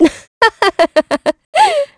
Ripine-Vox_Happy4_kr.wav